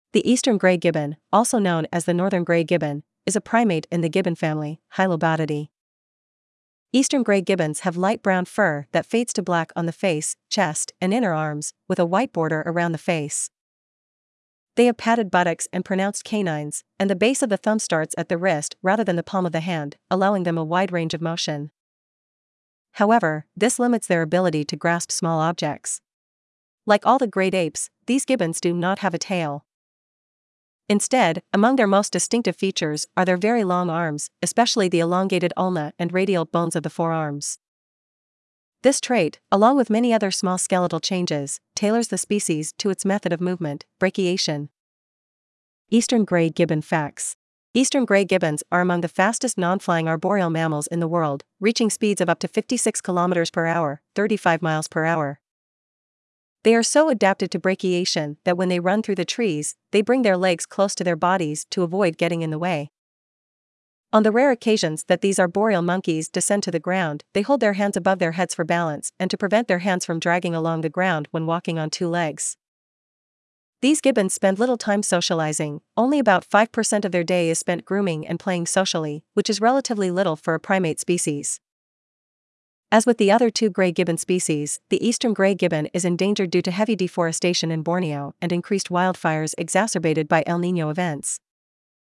Eastern Gray Gibbon
Eastern-Gray-Gibbon.mp3